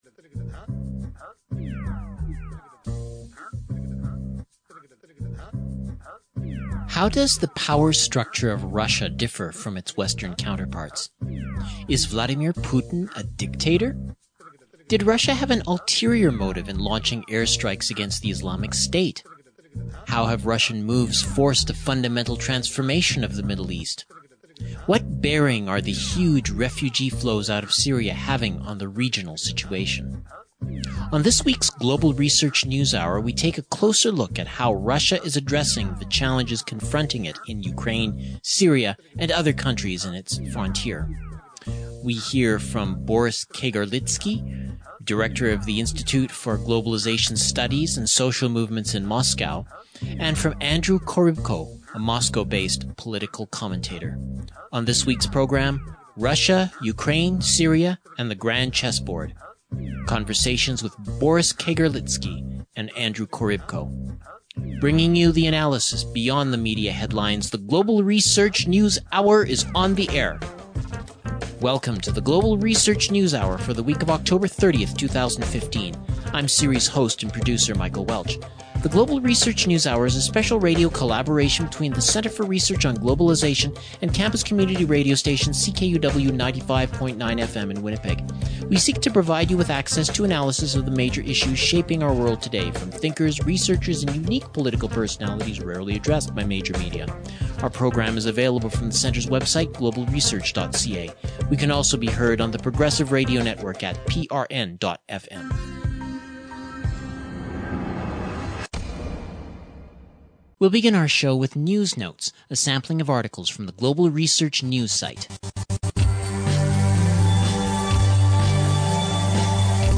Two political observers discuss Russian Geo-strategy